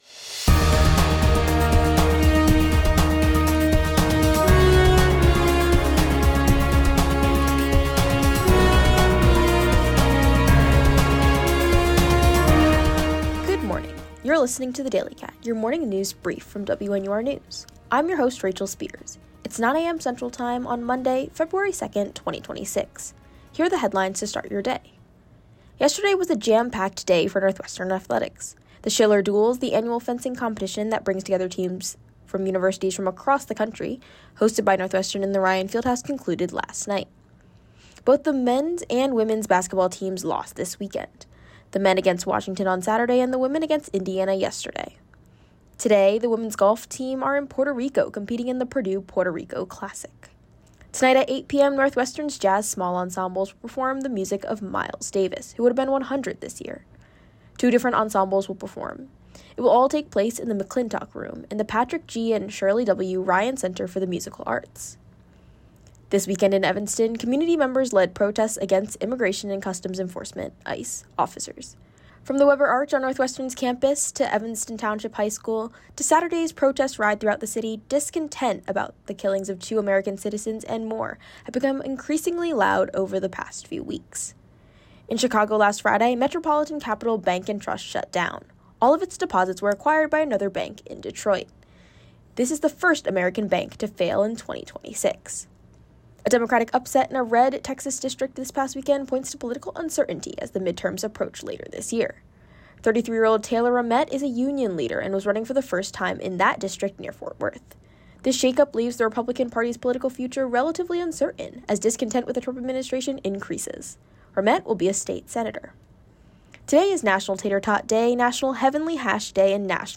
Monday February 2, 2026: Northwestern sports, ICE protests, bank failures, Groundhog Day. WNUR News broadcasts live at 6 pm CST on Mondays, Wednesdays, and Fridays on WNUR 89.3 FM.